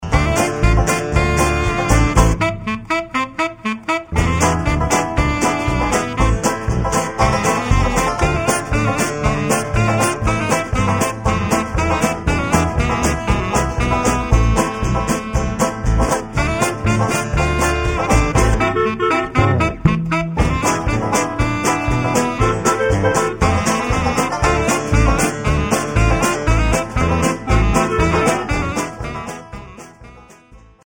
• Качество: 192, Stereo
веселые
саундтреки
без слов
инструментальные
Folk Rock
из игр
банджо